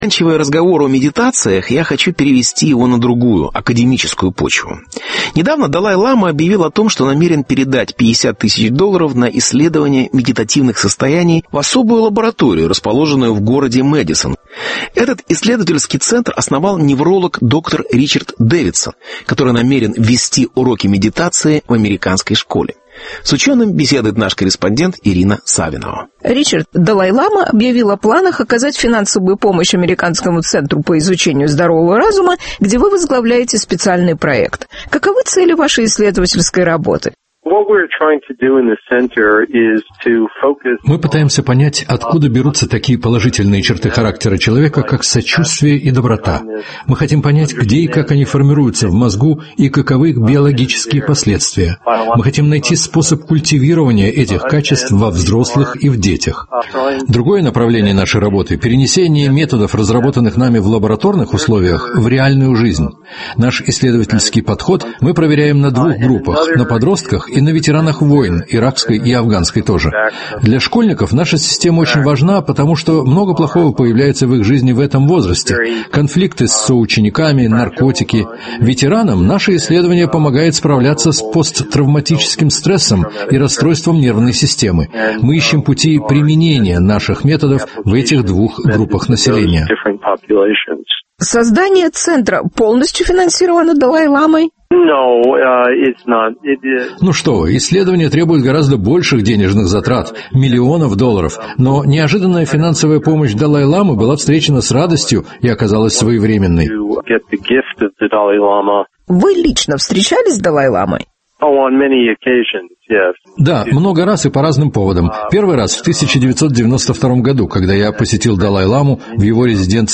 Беседа с неврологом Ричардом Дейвидсоном